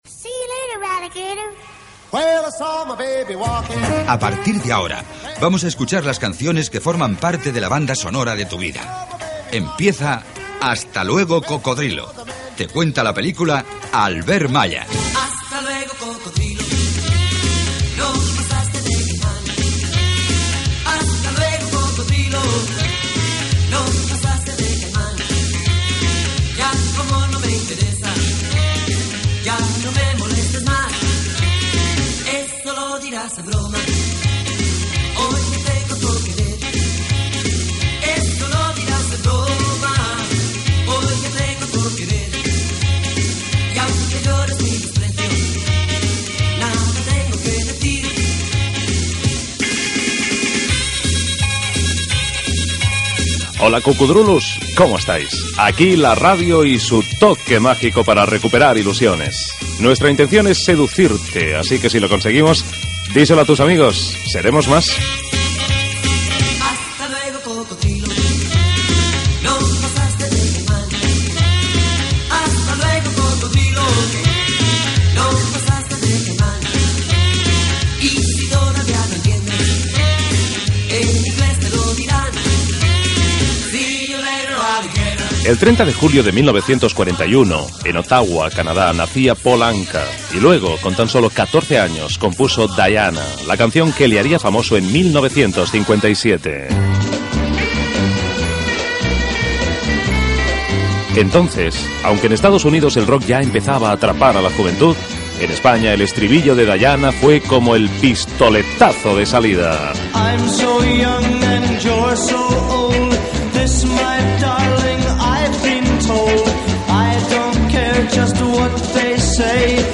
Careta, sintonia, presentació de la primera edició del programa, tema musical de 1957, identificació del programa, dades sobre Paul Anka i Elvis Presley
Musical